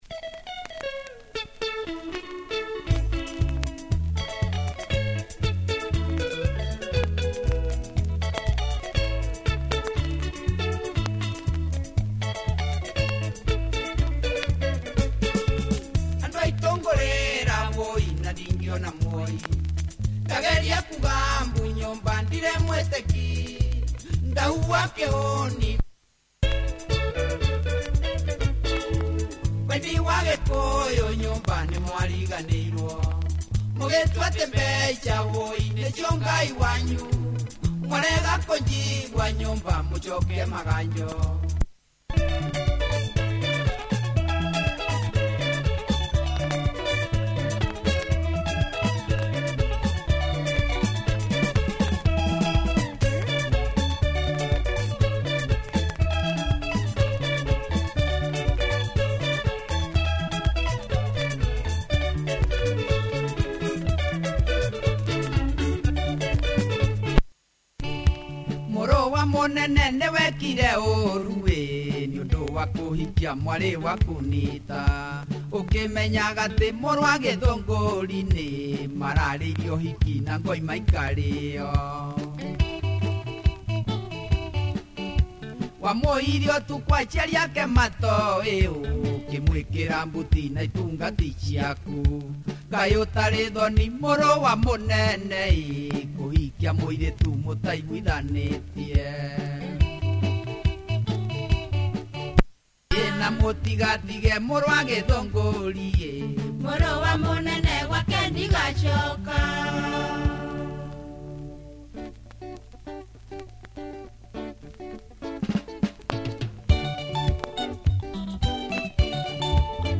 Kikuyu Benga